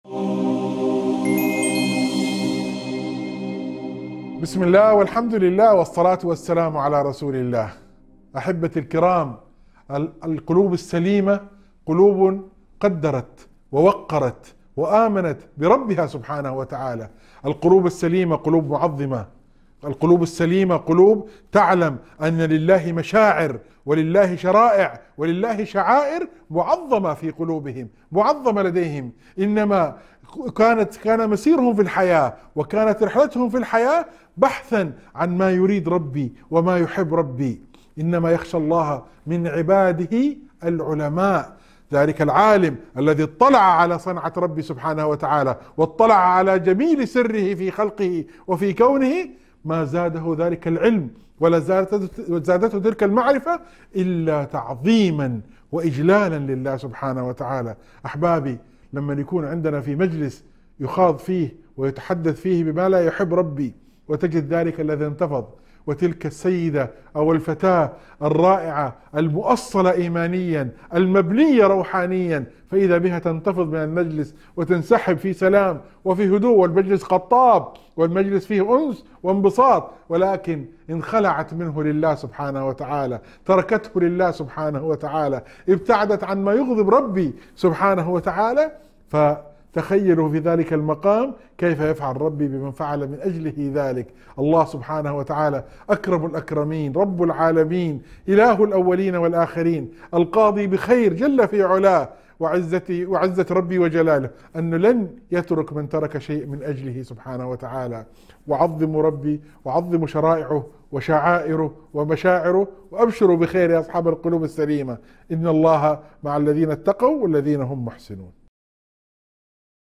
** موعظة مؤثرة تُبرز صفات القلب السليم الذي يعظم الله ويخشاه، وتُظهر قيمة العالم الذي يزداد علمه تعظيماً للخالق. تلفت الانتباه إلى أهمية اختيار المجالس التي ترضي الله والابتعاد عما يغضبه، مع بشارة بأن الله مع المتقين المحسنين.